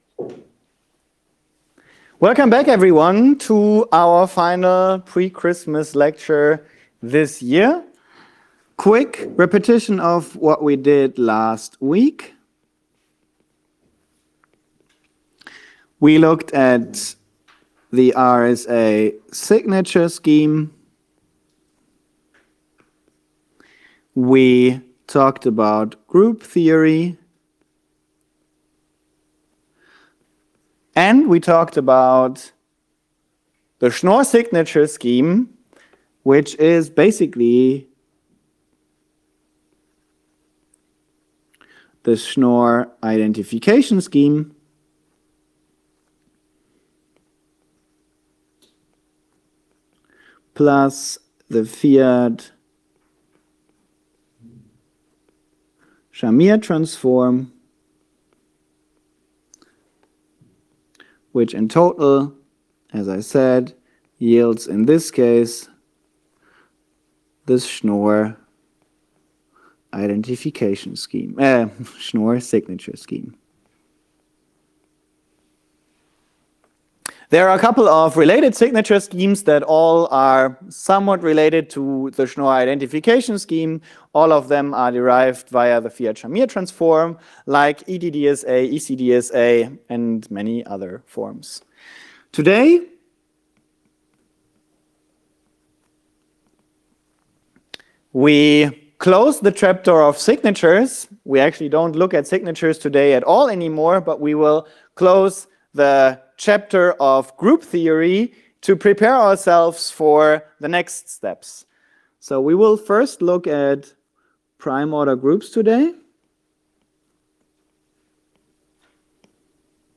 Vorlesungsaufzeichnungen am Department Informatik